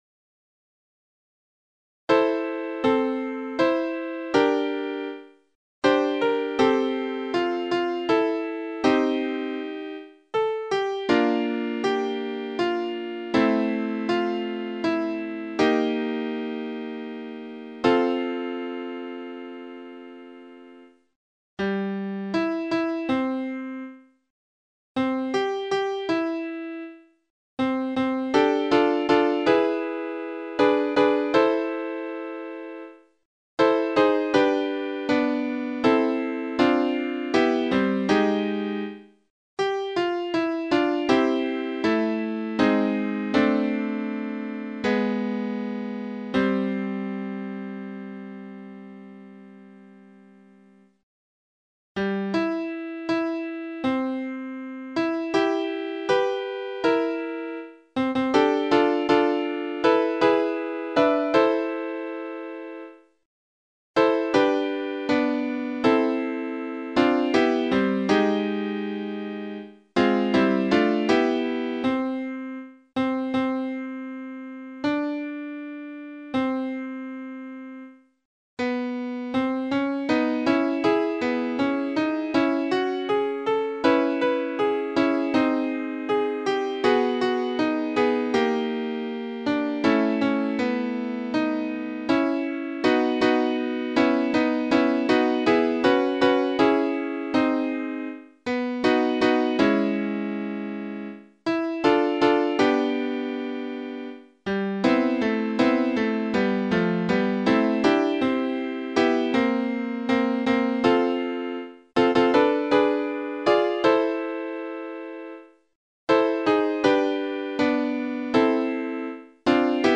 コーラスのオーディオファイル
３声